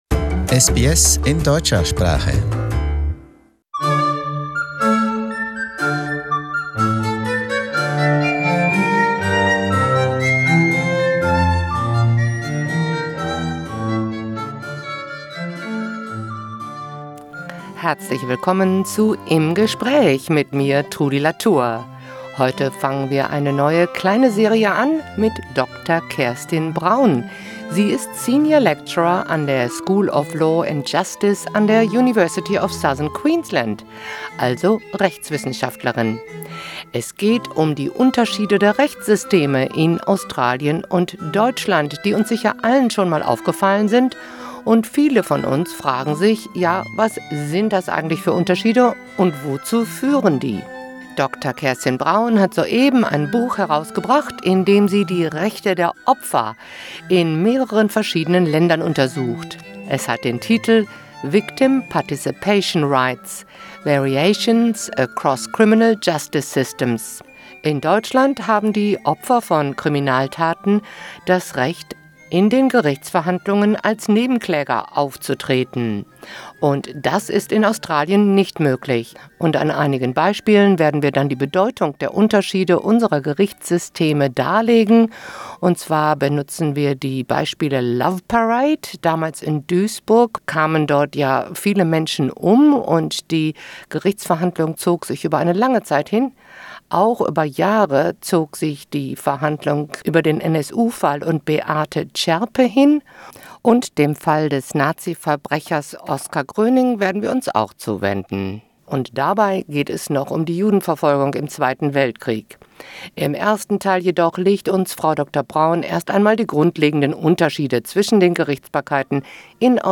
In conversation: The differences between the Australian and German legal systems